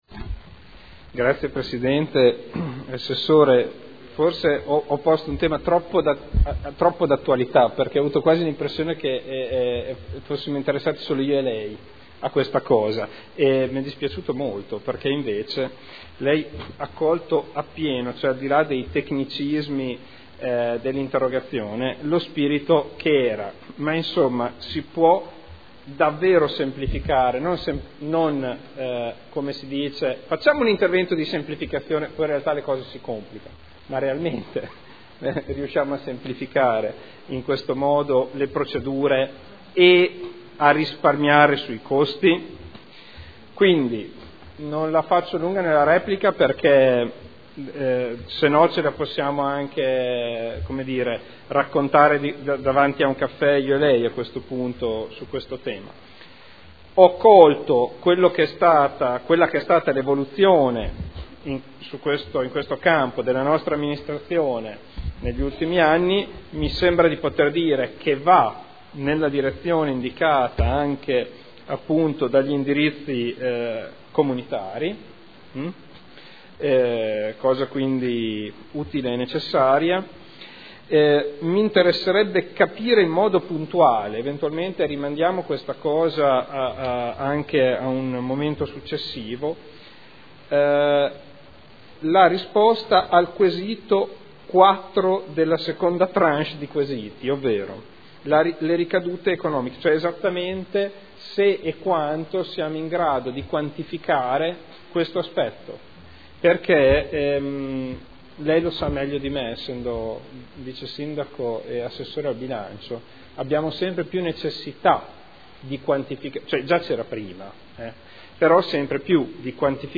Seduta del 22/10/2012. Conclude interrogazione del consigliere Ricci (Sinistra per Modena) avente per oggetto: “E-procurement”